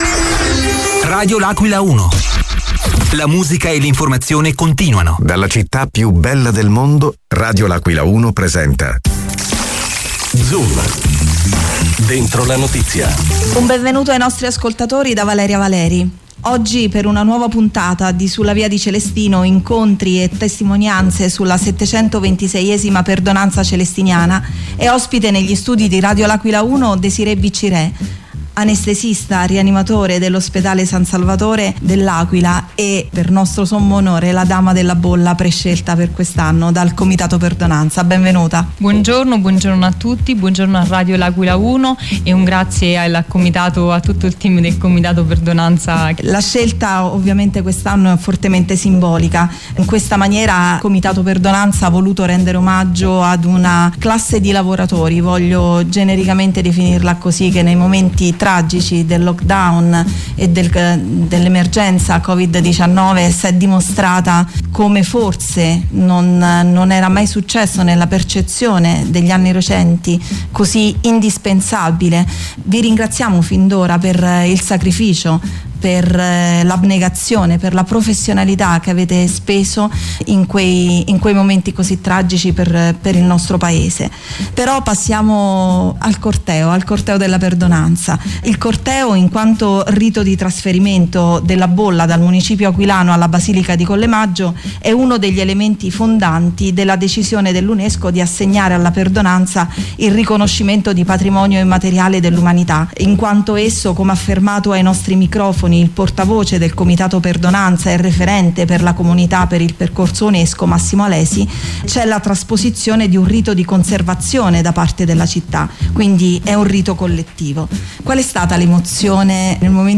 ospite negli studi di Radio L’Aquila 1